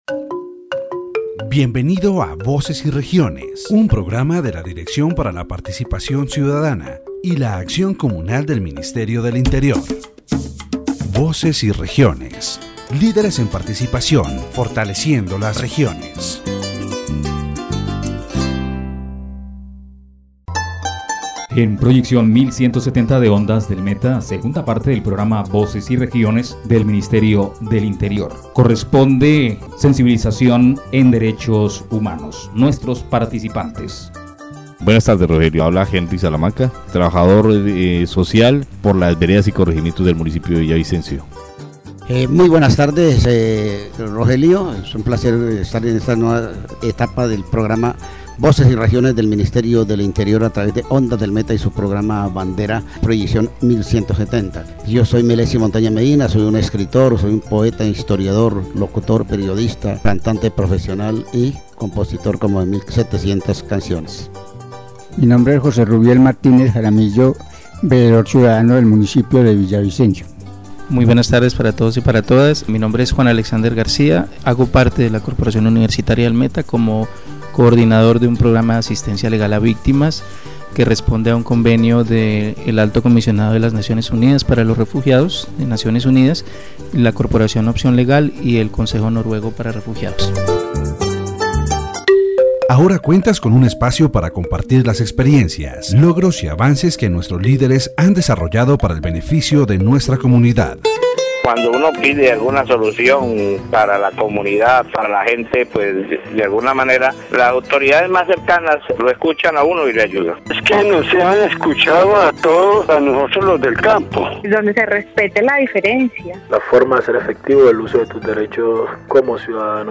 The radio program "Voces y Regiones" delves into the critical issue of human rights in Colombia, focusing specifically on the challenges faced by internally displaced people (IDPs) due to the armed conflict. The debate highlights the government's efforts to address these issues through policies and programs, such as the creation of a special unit for victims.